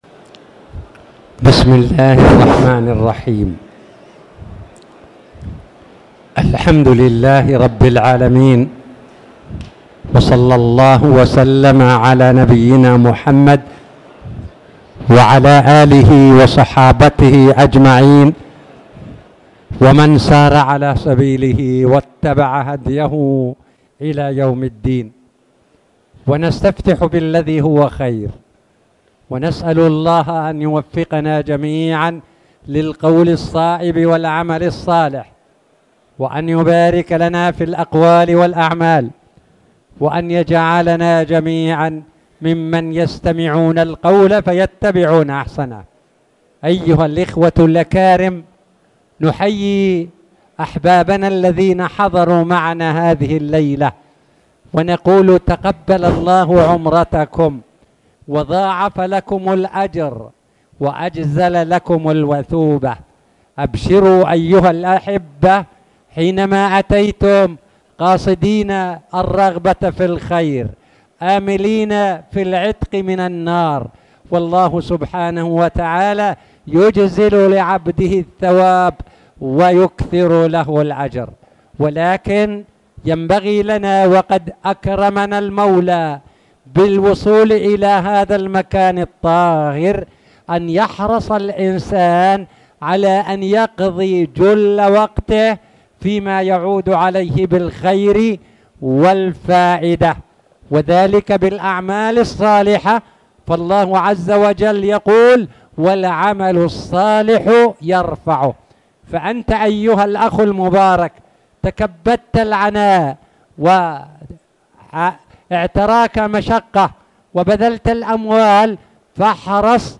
تاريخ النشر ١٦ رجب ١٤٣٨ هـ المكان: المسجد الحرام الشيخ